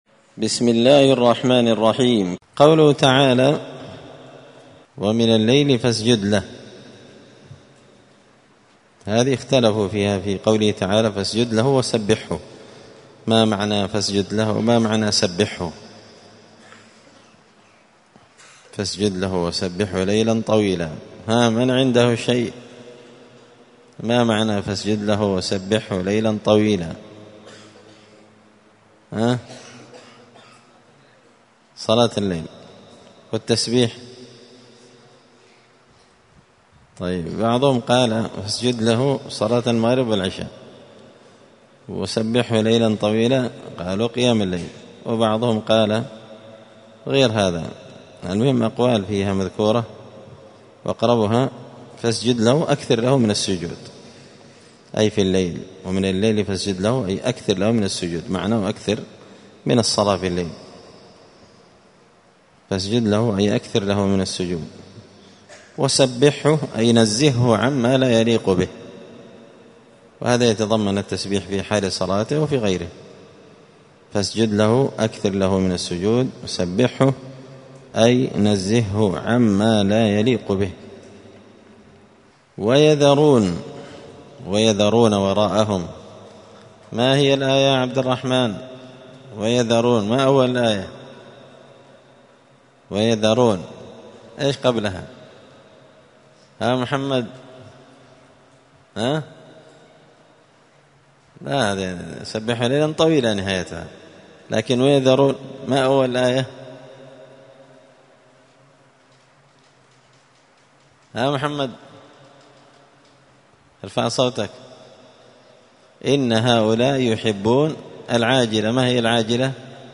مسجد الفرقان قشن_المهرة_اليمن 📌الدروس اليومية